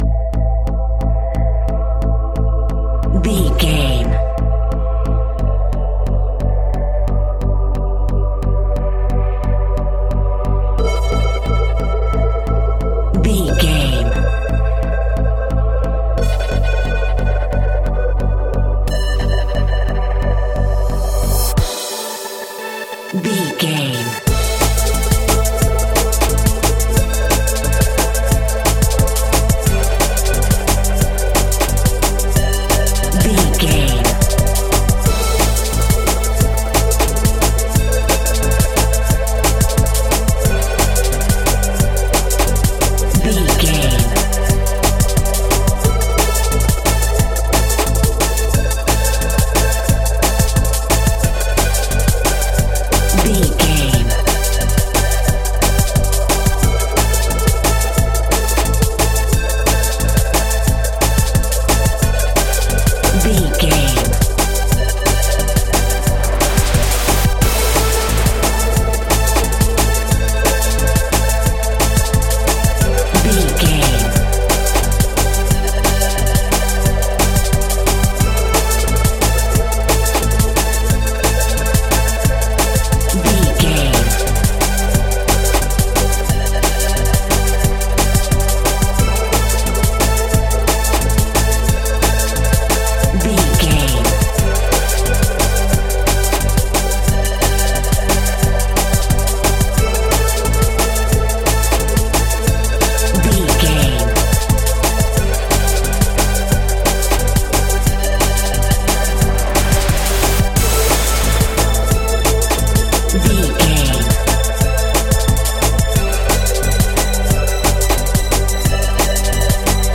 Aeolian/Minor
Fast
aggressive
dark
driving
energetic
intense
drum machine
synthesiser
electronic
sub bass
synth leads
synth bass